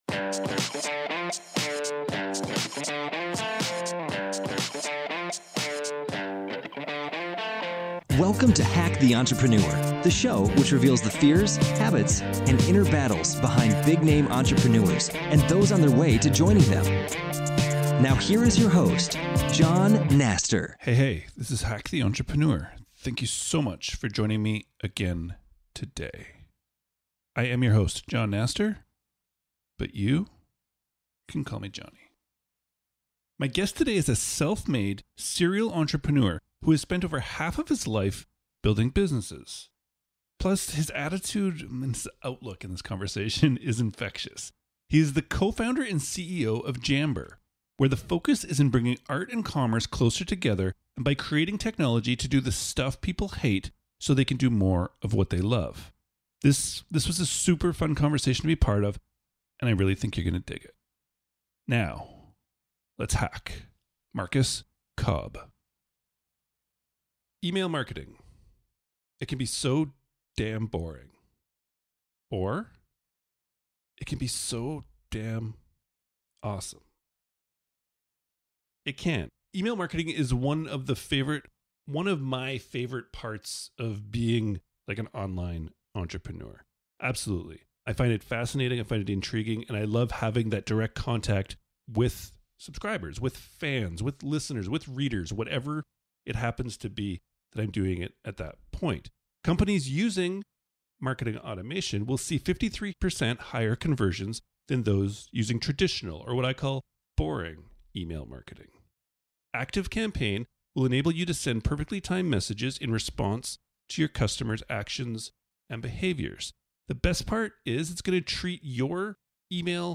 This was a super fun conversation to be part of, and I think you are going to dig it.